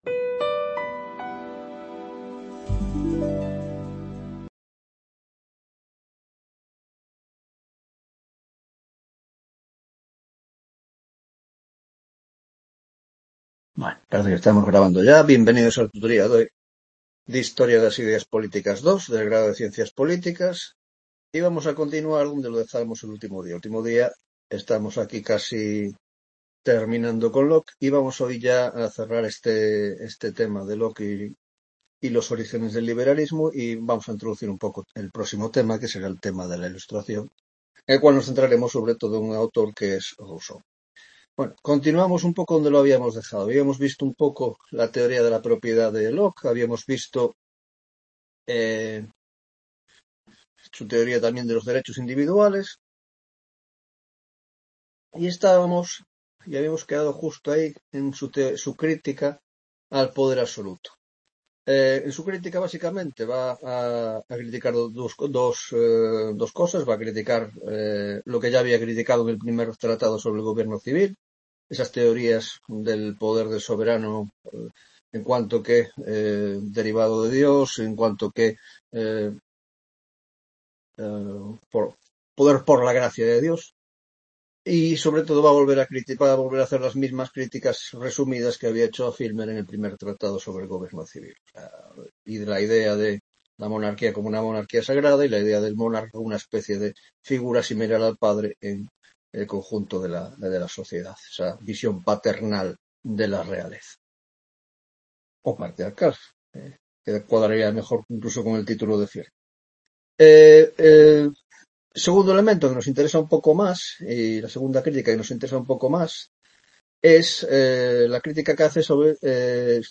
4ª Tutoria de Historia de las Ideas Políticas 2